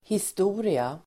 Uttal: [hist'o:ria]